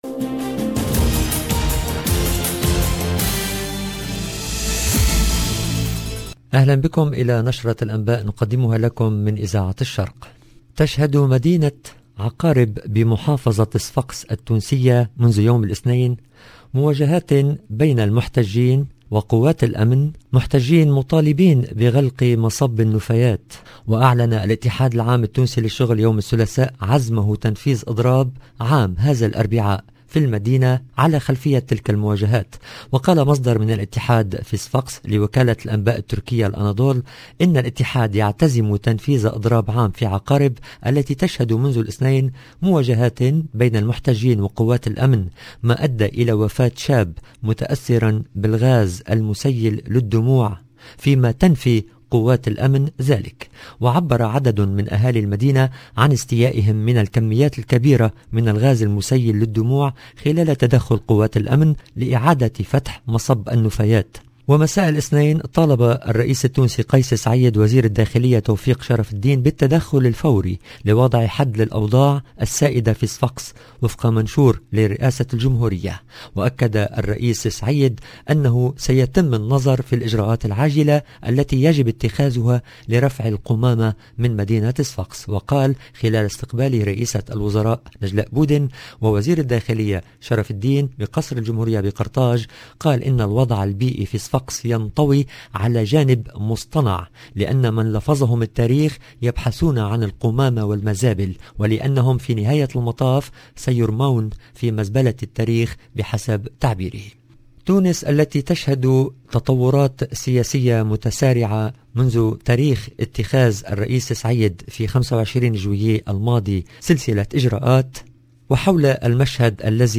LE JOURNAL DU SOIR EN LANGUE ARABE DU 9/11/2021